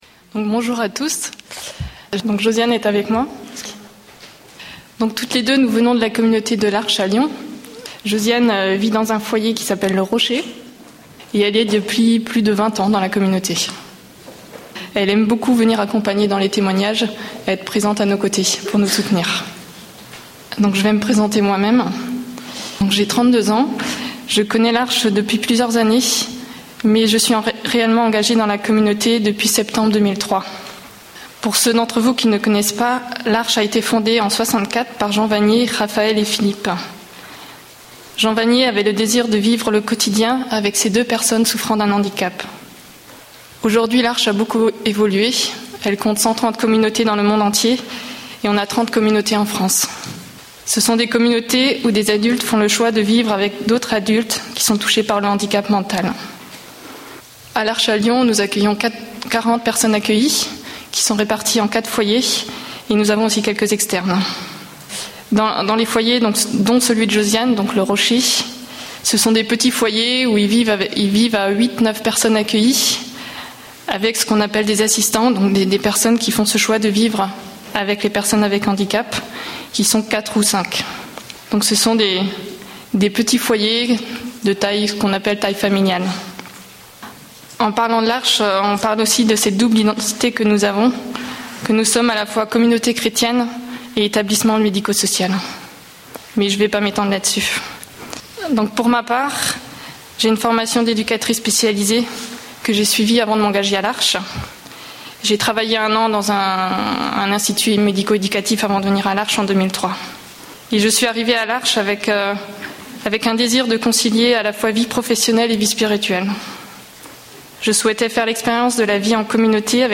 Corps de chair, corps de r�surrection ? (Th�me du 11� colloque du Centre Silo� 2011)